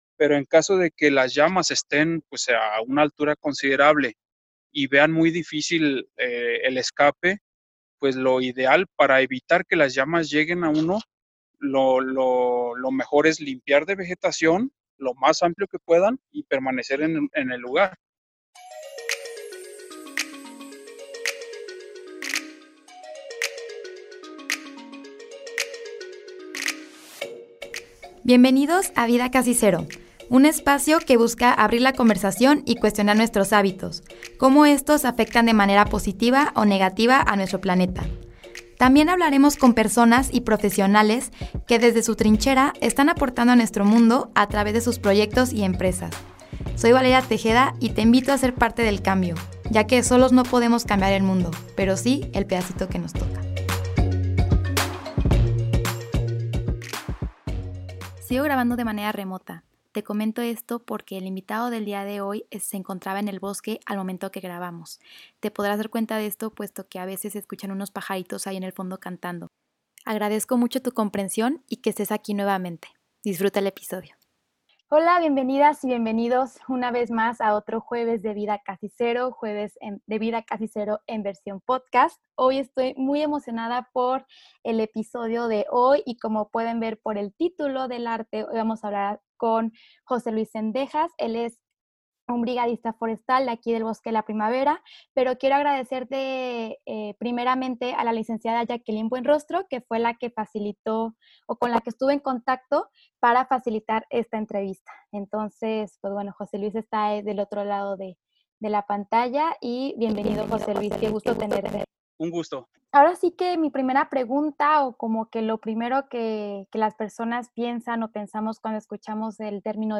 Hoy tuve el placer de platicar con uno de ellos, uno de las tantas personas que diariamente cuidan el Bosque de La Primavera aquí en la ZMG. Te invito a que compartas este episodio para que más personas se enteren de su labor y de cómo reaccionar adecuadamente ante un incendio forestal.